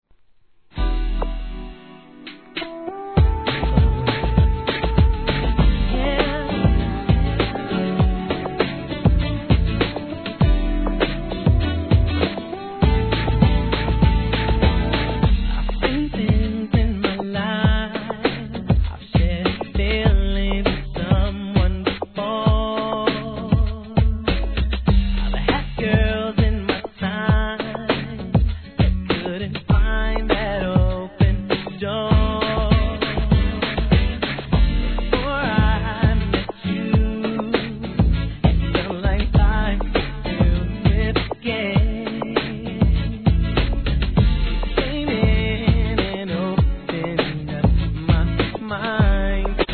1. HIP HOP/R&B
女の子だけを第一義に作られたスーパー・ラブリー・テンダー6曲いり第二弾!!